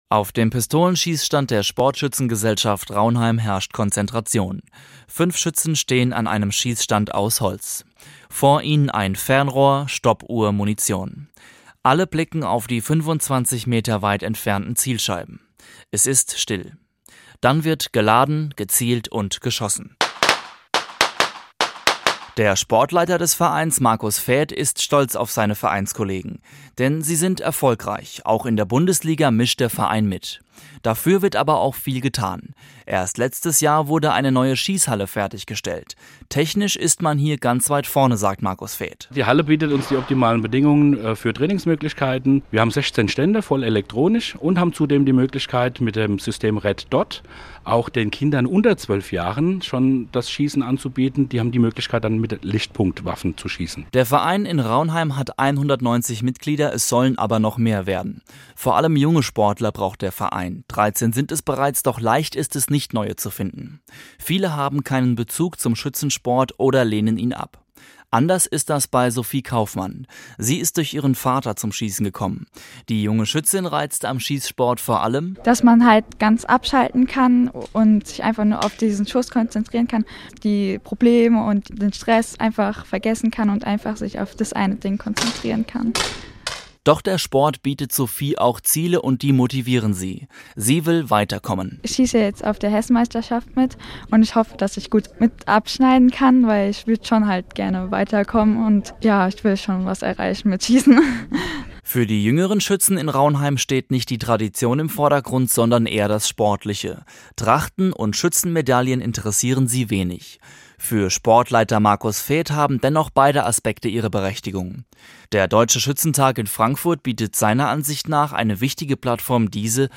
HR4-Radiobeitrag 27.04.2017